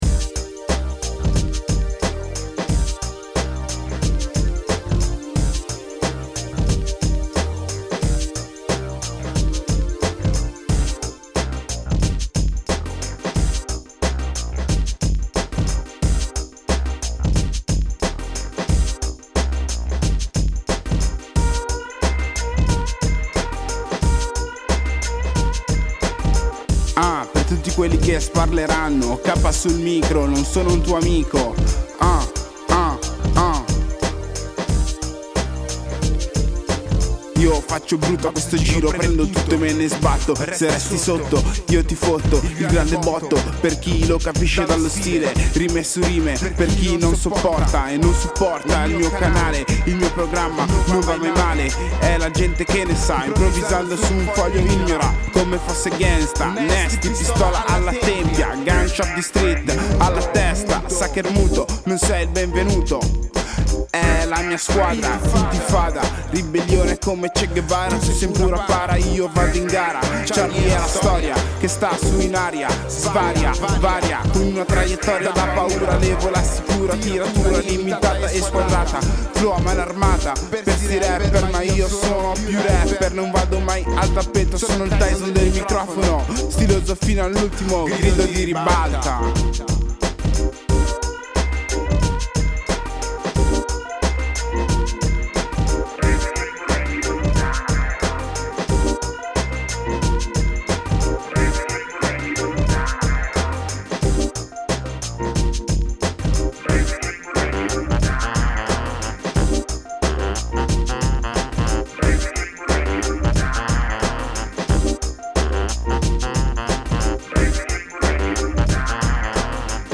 rapper
con questo brano dal gusto elettronico prodotto presso lo studio della Redazione Radio Jeans del Centro Giovani Chiavari